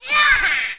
jump1.wav